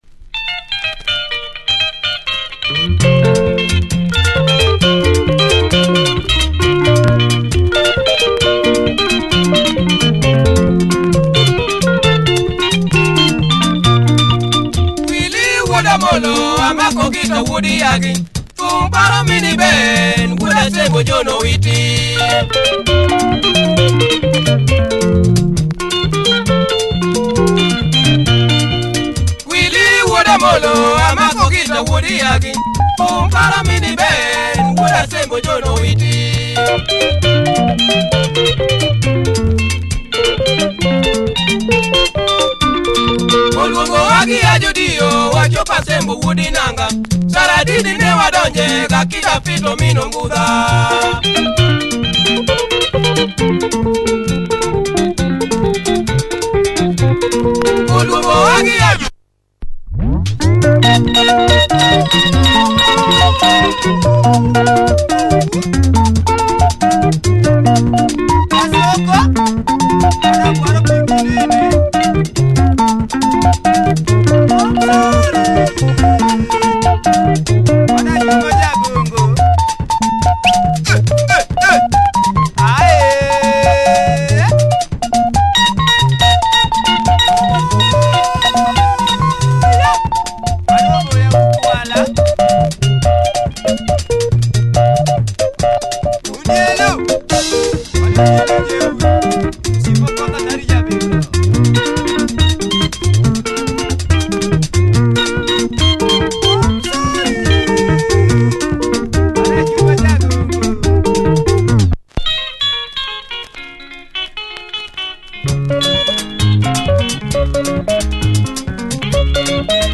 Distinct guitar sounds by this Luo Benga outfit.